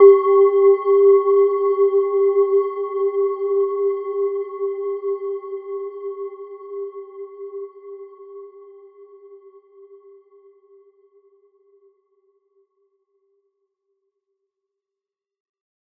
Gentle-Metallic-4-G4-f.wav